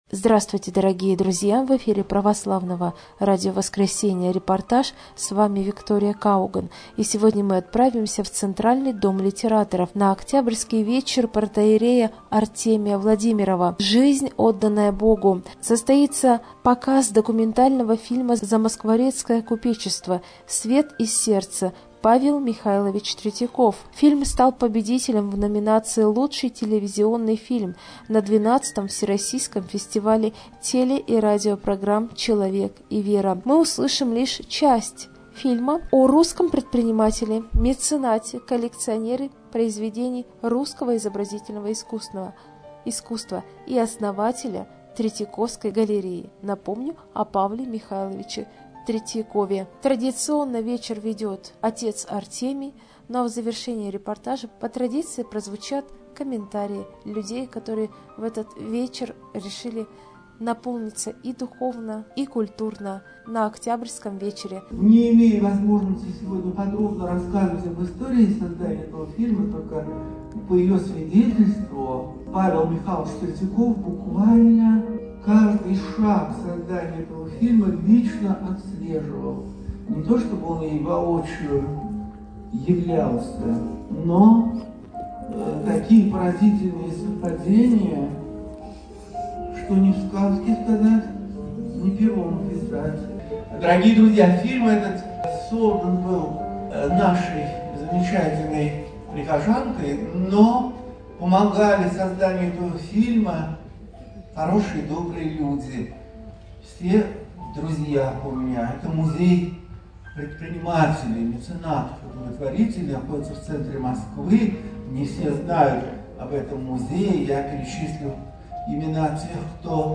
Творческий вечер